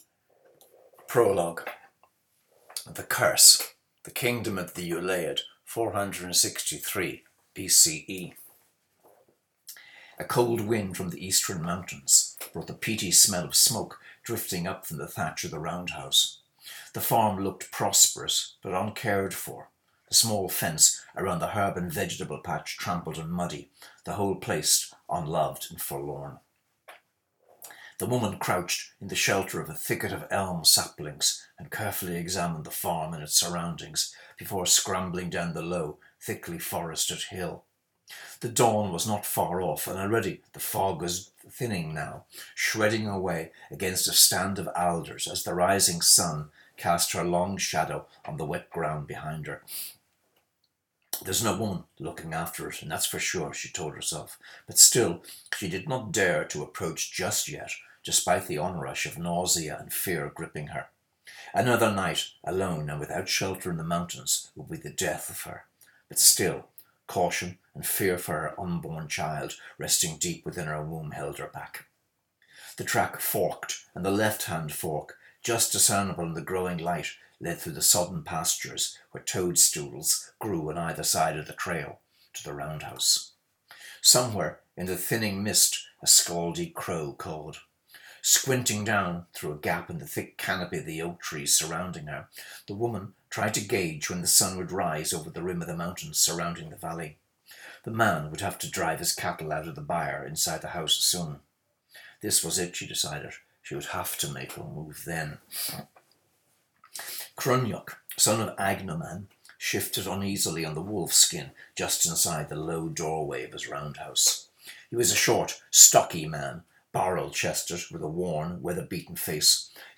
I haven’t done an audio recording of Raiding Cooley for a while – and it would feel a bit too strange to do a video recording of me reading, so I think I’ll give that a miss for now.